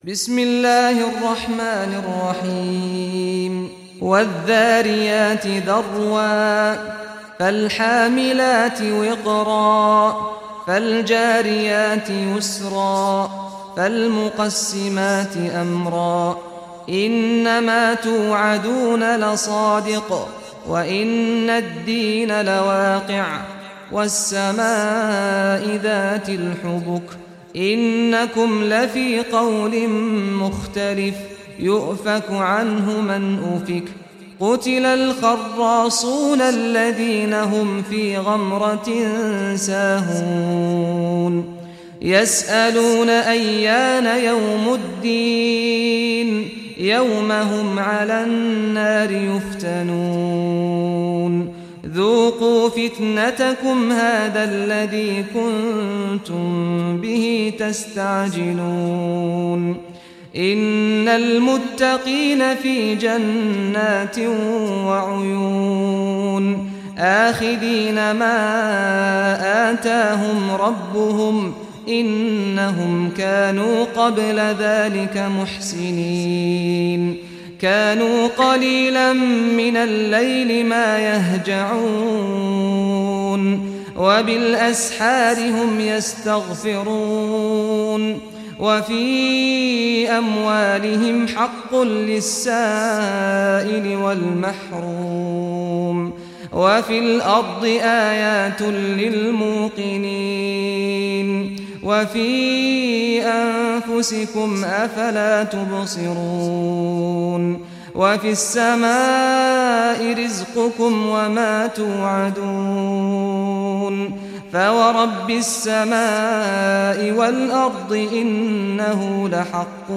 Surah Dhariyat Recitation by Sheikh Saad Ghamdi